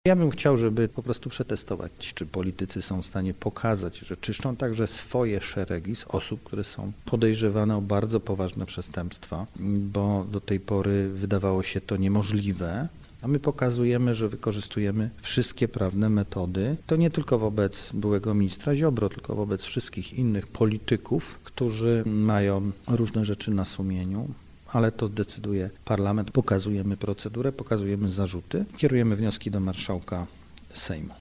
W rozmowie z Polskim Radiem wyjaśnił, że chciałby, aby Trybunał Stanu wreszcie zaczął realizować swoje konstytucyjne zadania: